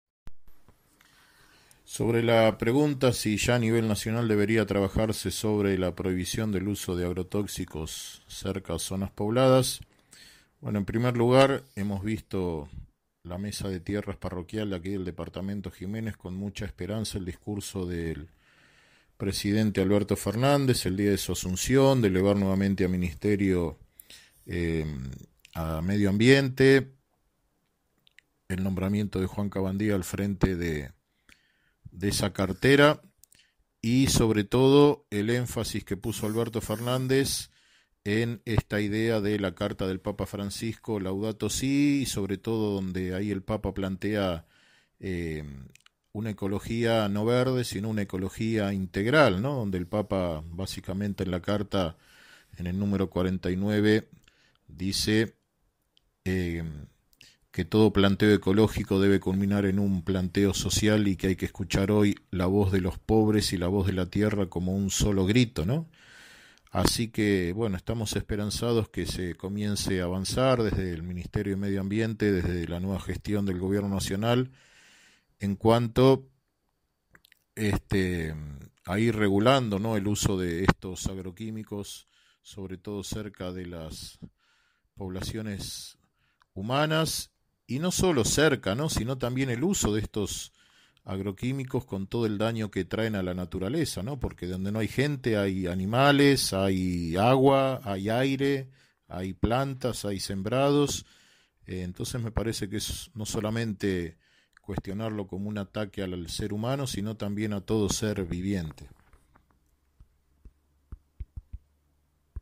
Una entrevista realizada por InfoBaires24 se adentra a la realidad que padecen en Santiago del Estero, los pobladores en zonas donde el uso del agrotóxico y las fumigaciones aéreas se cobra vidas humanas y daños forestales de importancia.